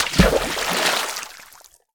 • Water is still low quality, I have provided a high quality version of the same sound effect that you can implement.
watersplash.wav